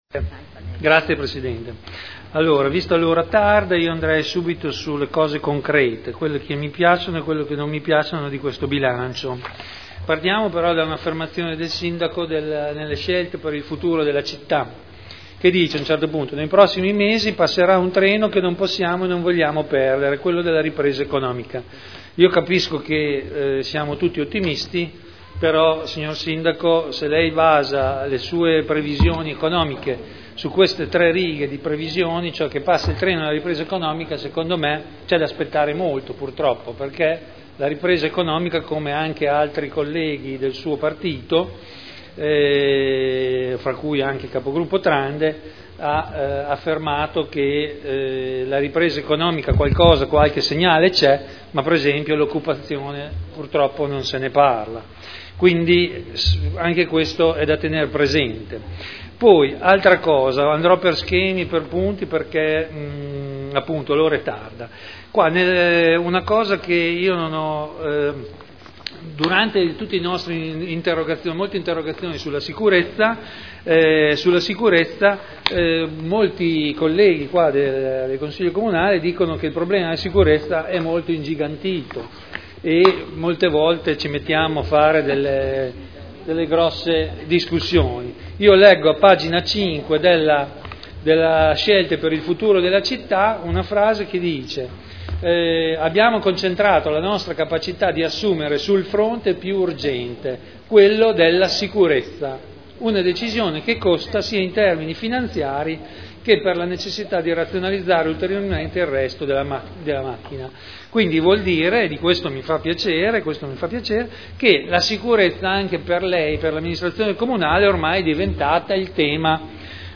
Seduta del 28/03/2011. Dibattito sul Bilancio.
Audio Consiglio Comunale